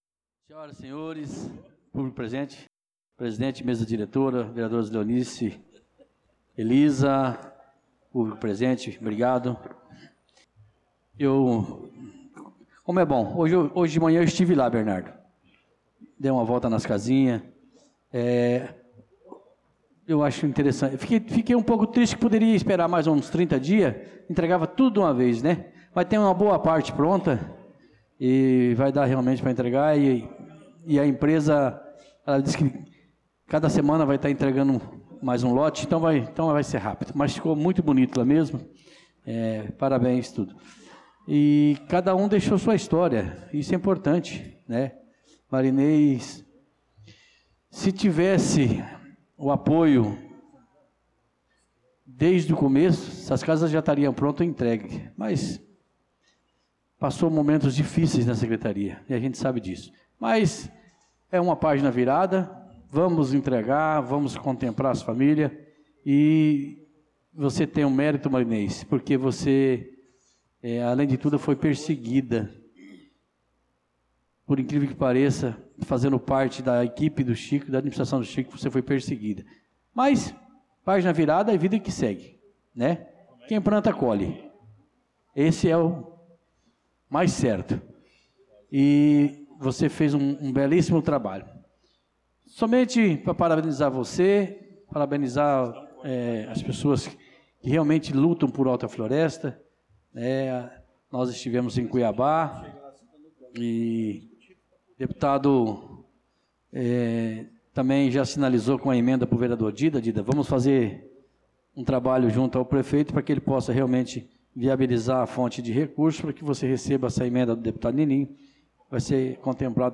Pronunciamento do vereador Tuti na Sessão Ordinária do dia 02/06/2025